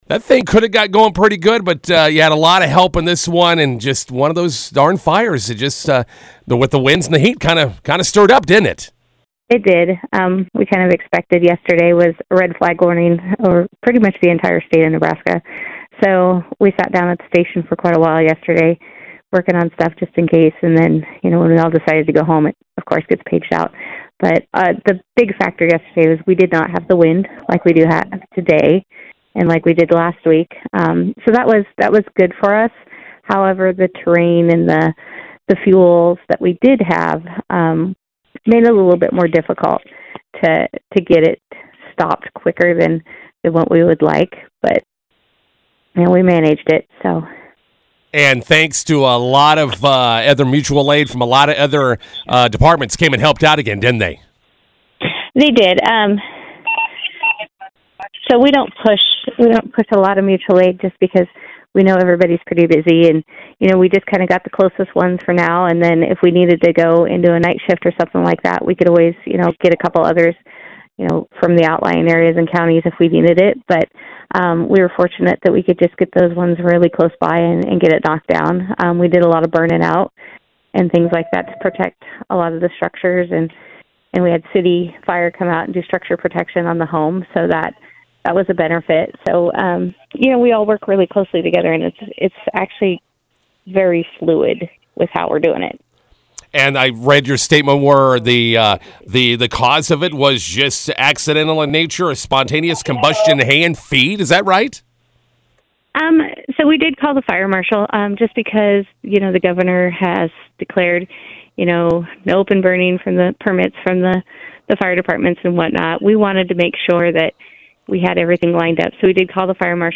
INTERVIEW: Firefighters respond to Saturday fire south of McCook.